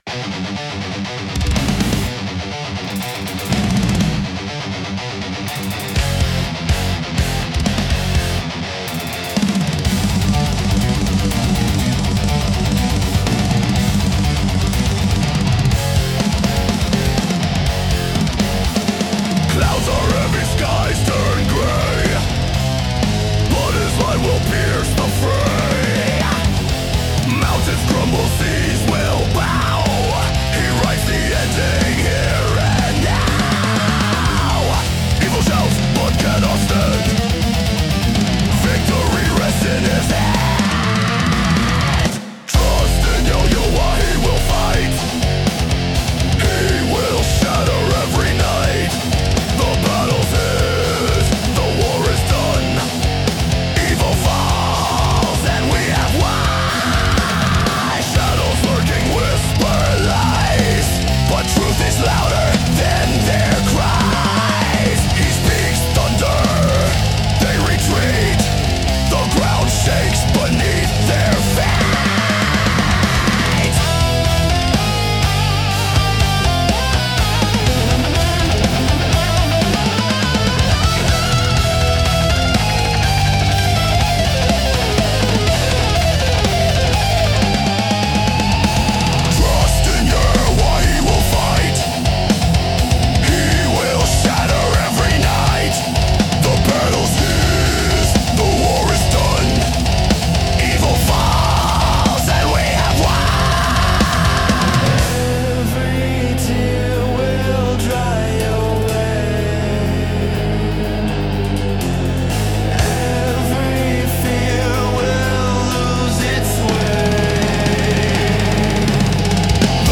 Collaboration • 2024
Brutal sound. Unwavering faith.